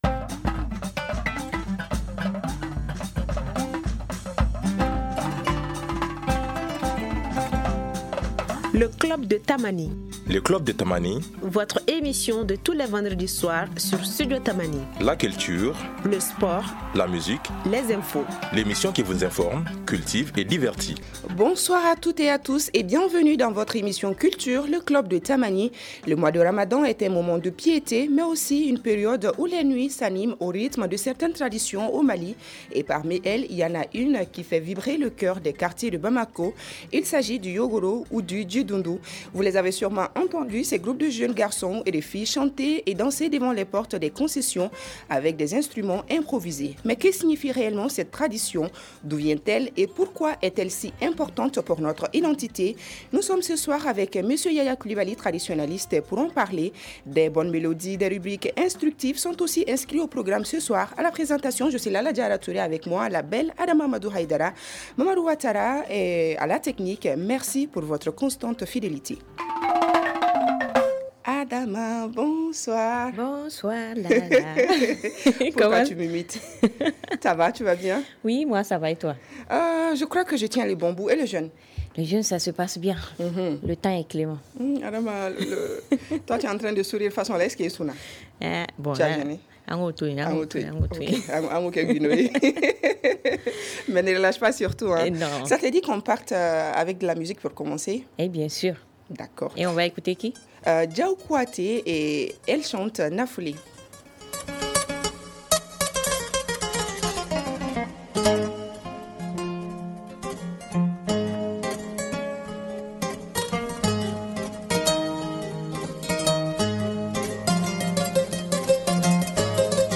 Écoutez l’ intégralité de l’émission Club Tamani :